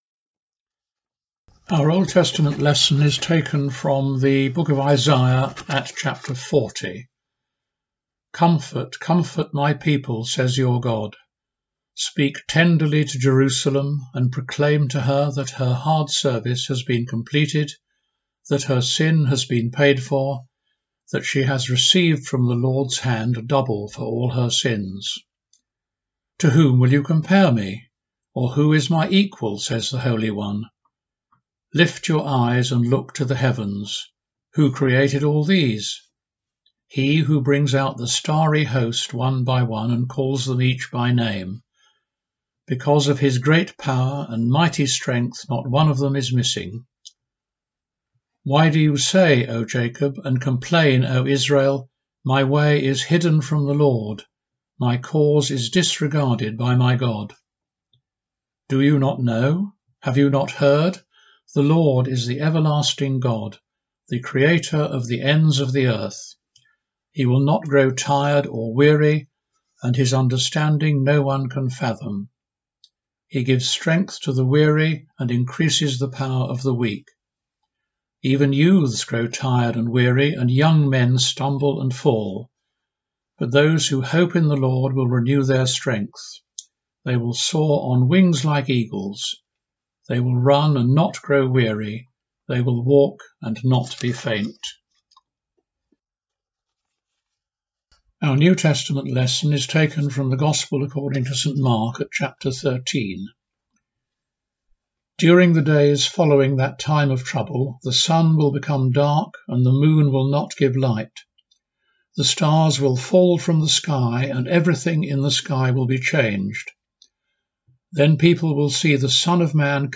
Welcome & Call to Worship